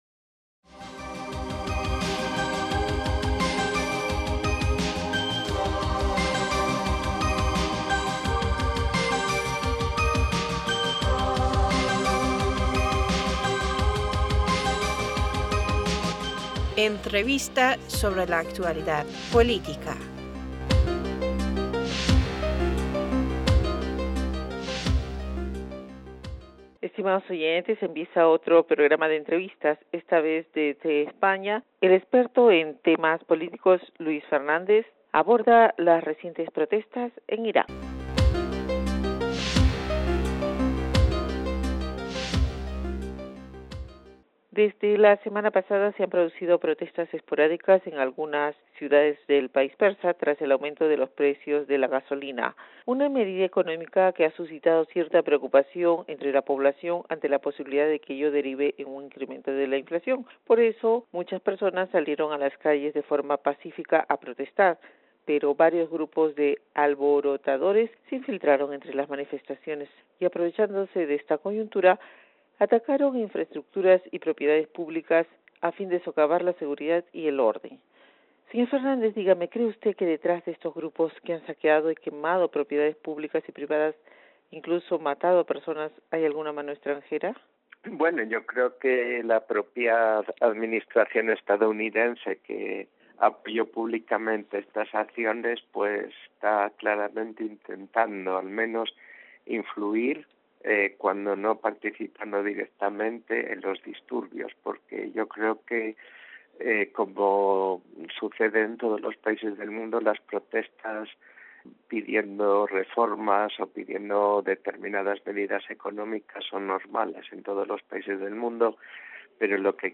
ParsToday- Entrevistador (E): Estimados oyentes, empieza otro programa de Entrevistas.